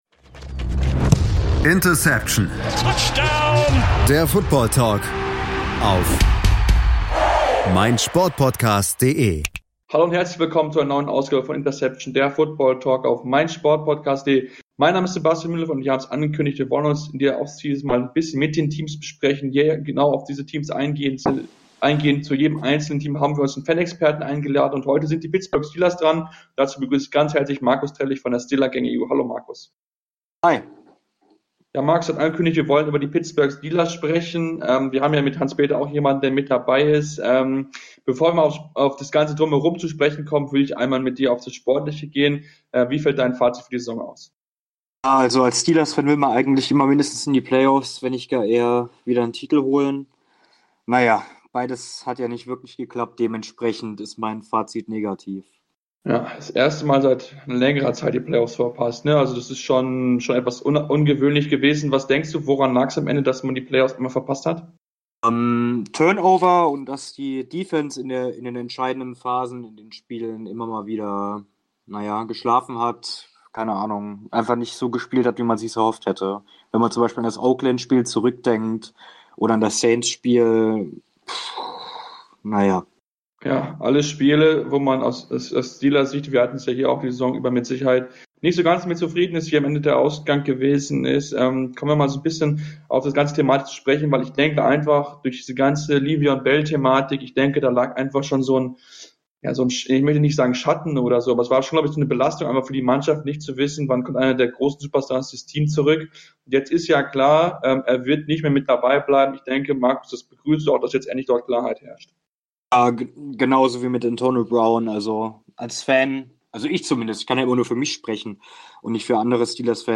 Es gibt eine Ausgabe zu jedem Team in der NFL, wo unsere Crew mit Fan-Experten über die jeweiligen Teams sprechen. Zu Beginn der Reihe beschäftigen wir uns mit den Teams aus der AFC.